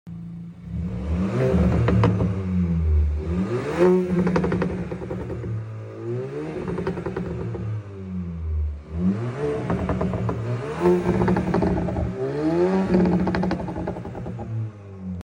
Audi A4 B9 1.4t came sound effects free download By psltuning 0 Downloads 2 months ago 15 seconds psltuning Sound Effects About Audi A4 B9 1.4t came Mp3 Sound Effect Audi A4 B9 1.4t came in for custom exhaust system with dual exhaust, burbles and crackles added with Rev limiter .